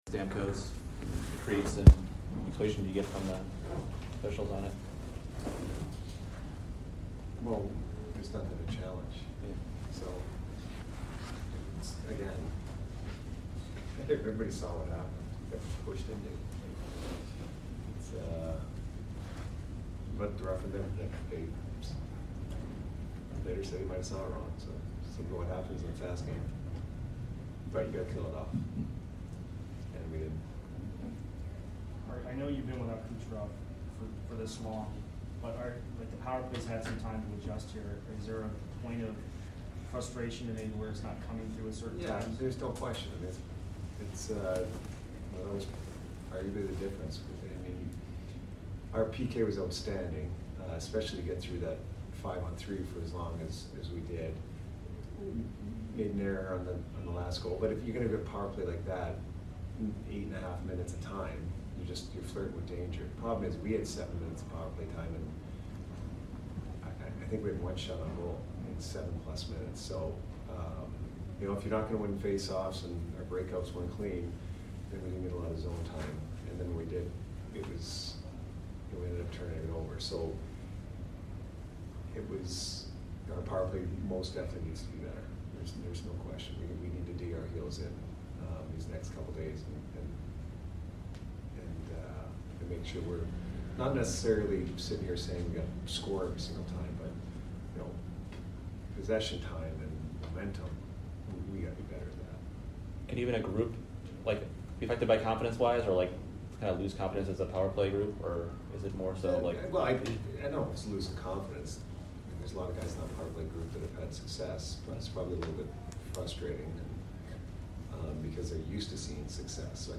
Head Coach Jon Cooper Post Game Vs CAR 11 - 9-21